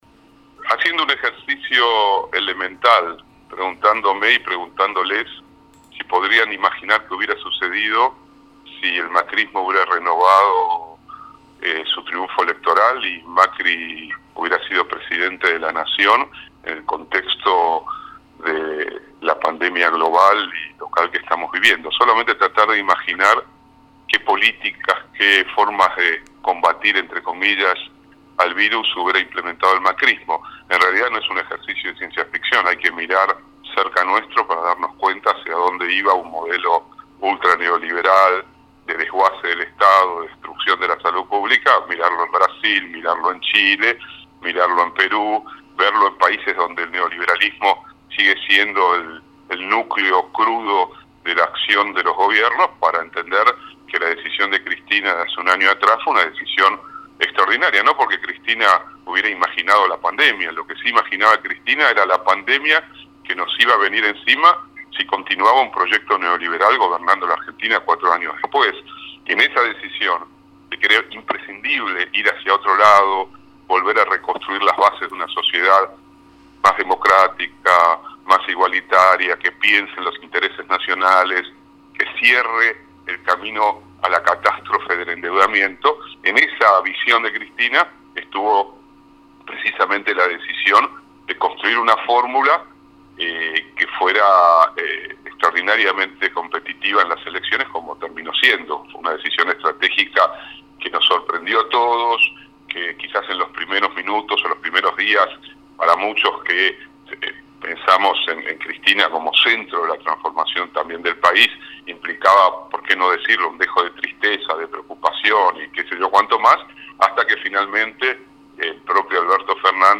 Ricardo Forster, filósofo, escritor e integrante del Consejo Asesor de Alberto Fernández, en diálogo con Proyecto Erre reflexionó sobre la decisión de Cristina Fernández de postular como presidente a Alberto Fernández, a un año del anuncio.